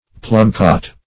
Plumcot \Plum"cot\, n. [Plum + apricot.] (Hort.)